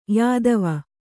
♪ yādava